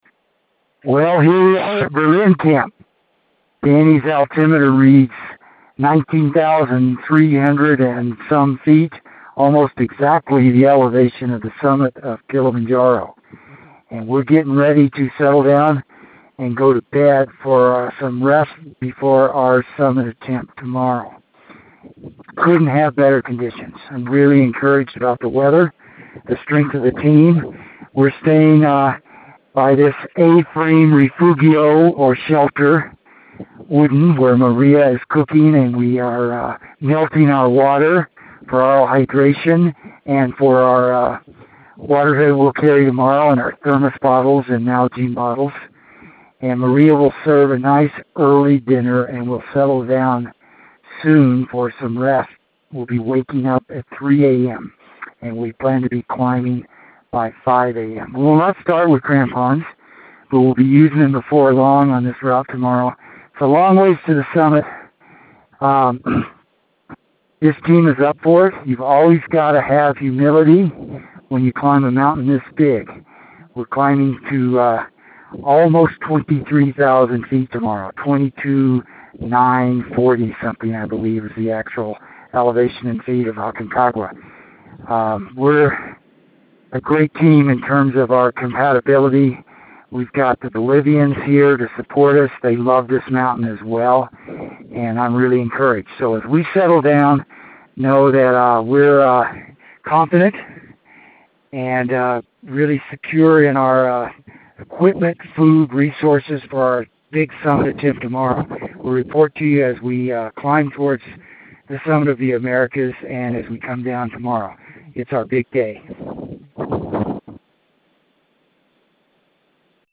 January 17, 2016 – At Berlin Camp – Tomorrow Is Our Big Day!